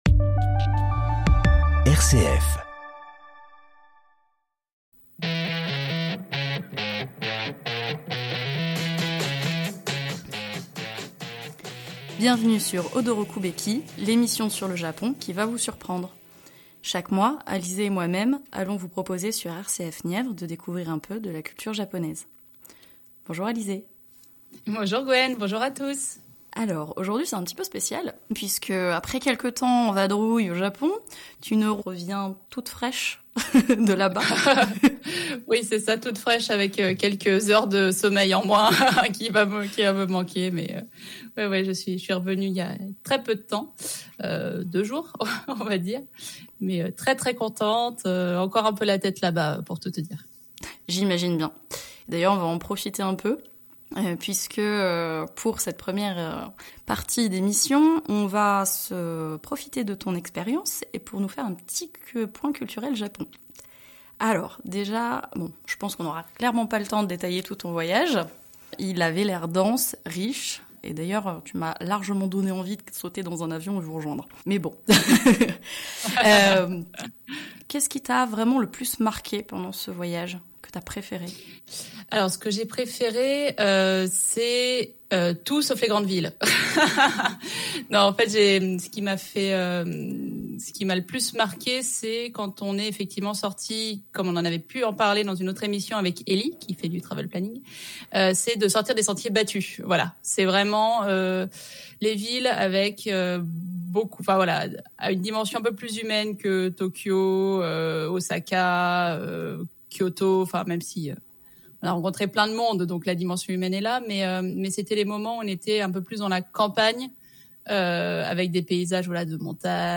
On a eu la chance de le rencontrer et de l’interviewer.